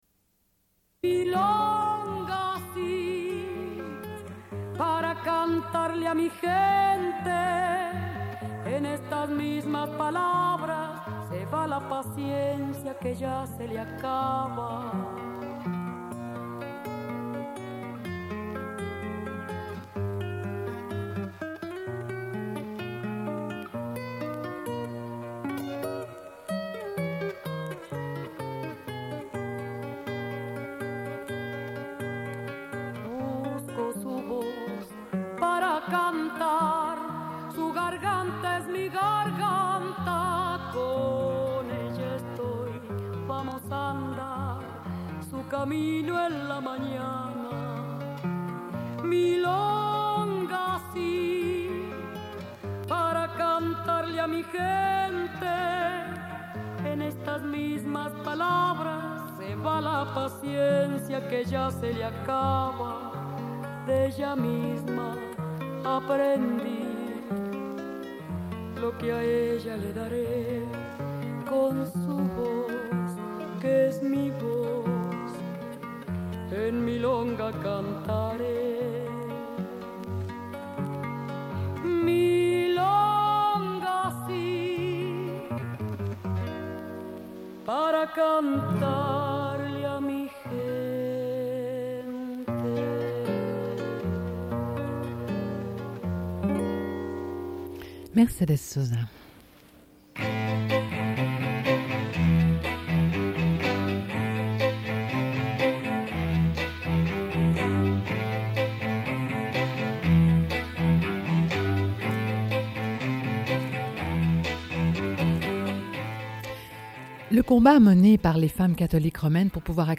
Une cassette audio, face B28:59